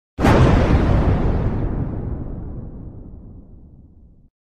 MLG EXPLOSION